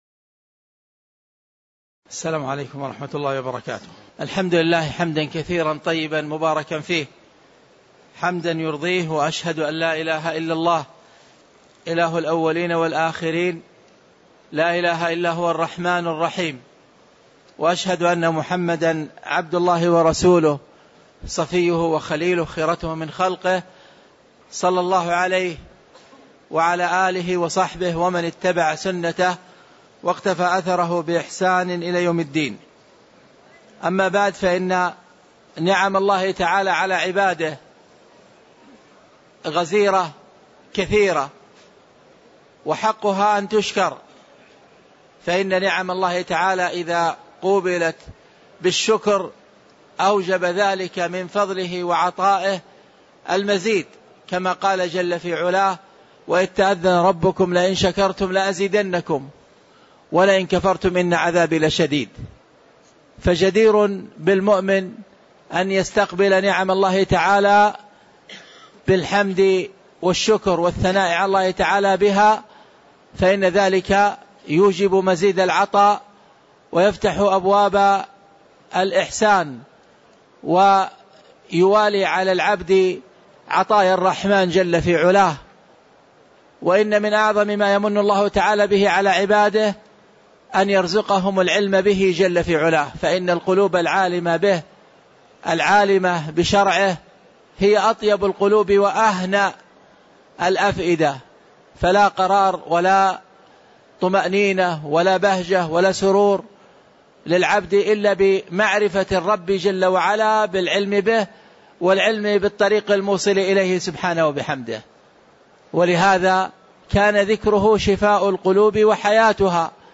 تاريخ النشر ١٩ شوال ١٤٣٧ هـ المكان: المسجد النبوي الشيخ